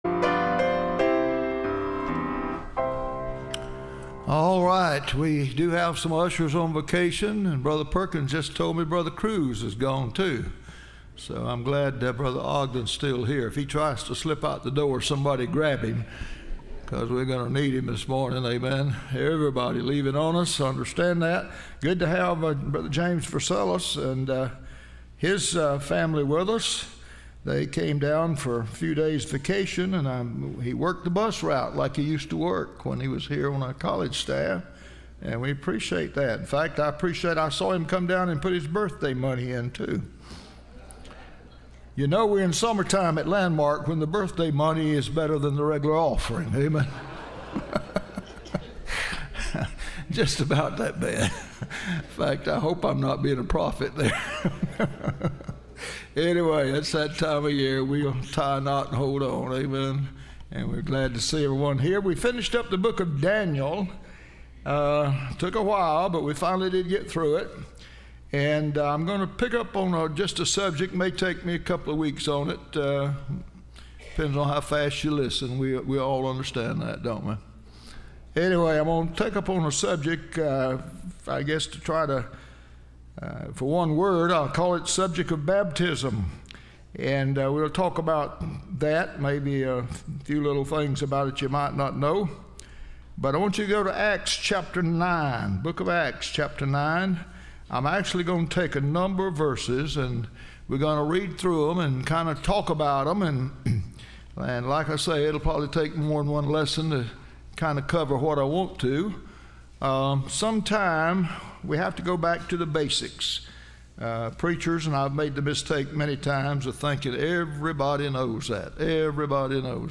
Series: Study of Baptism Service Type: Sunday School